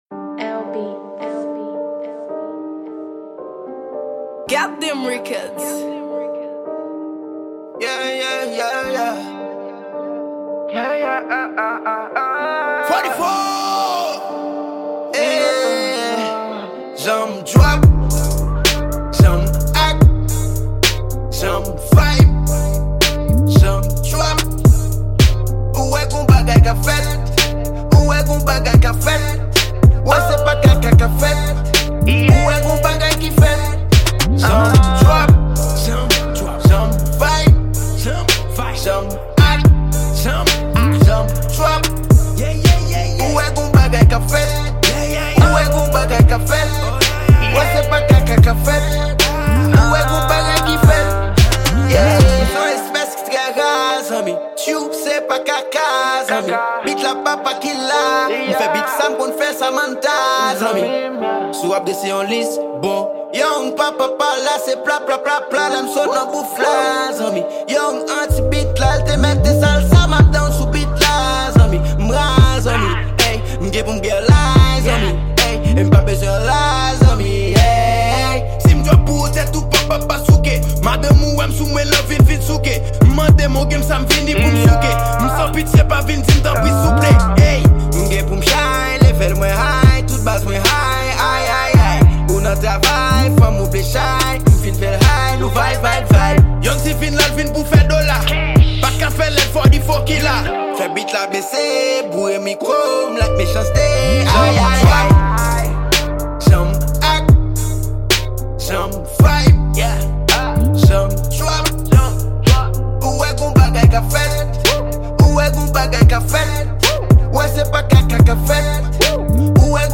Genre: Trap.